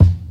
KICK_BAD_MUTHA.wav